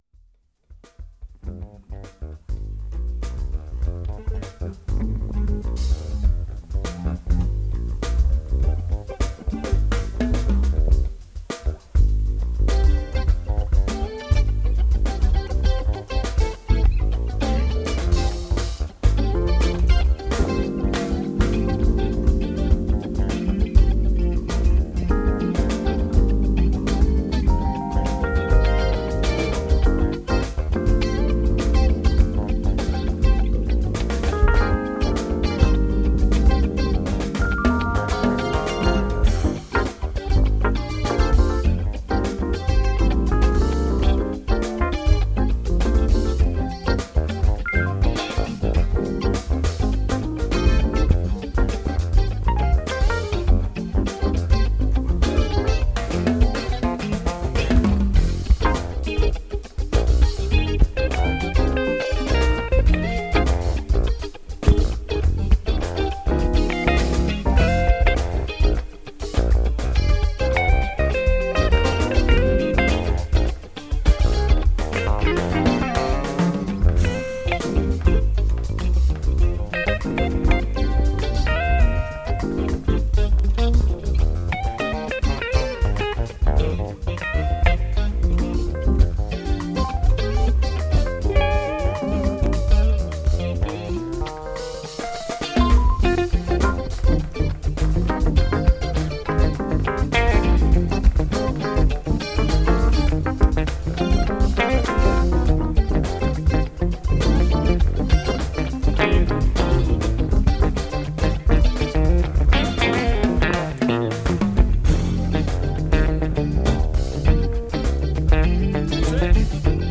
Have an 11-minute jam from a couple of evenings ago. Bass, drums, two guitarists and ...
Bass, drums, two guitarists and me on keys.